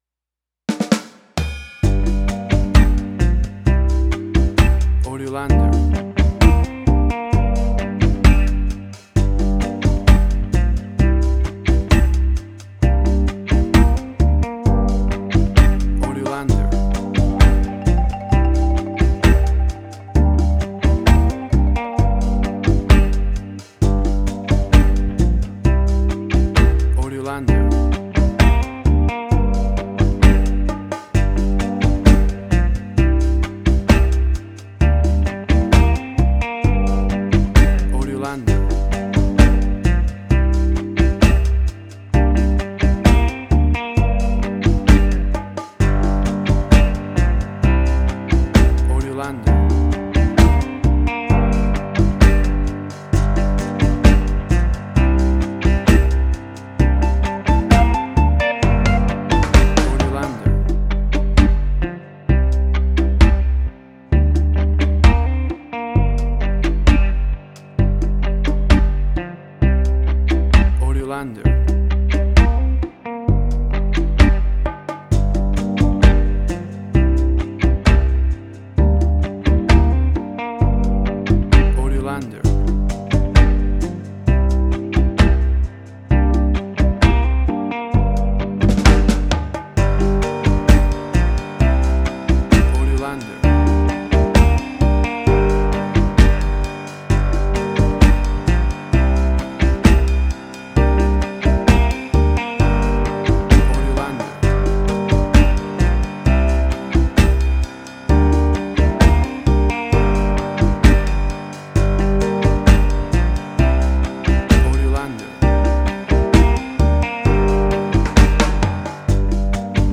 Reggae caribbean Dub Roots
Tempo (BPM): 65